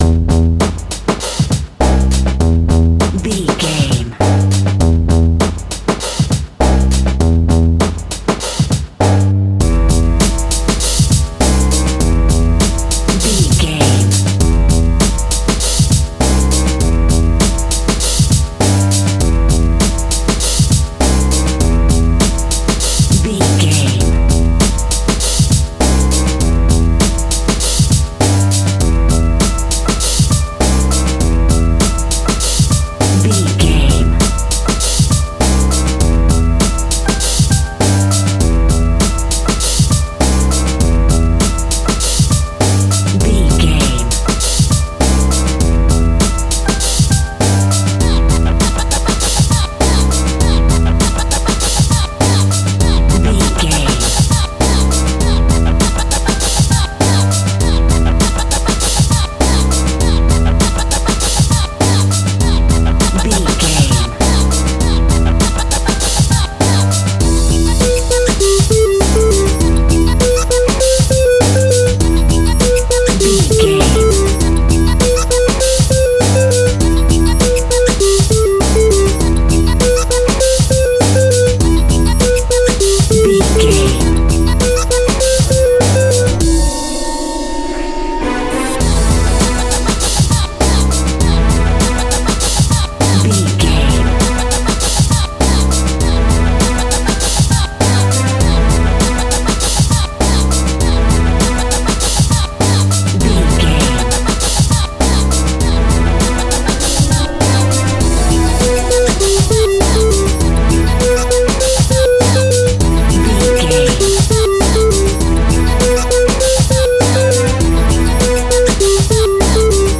Aeolian/Minor
hip hop
hip hop instrumentals
downtempo
synth lead
synth bass
synth drums
turntables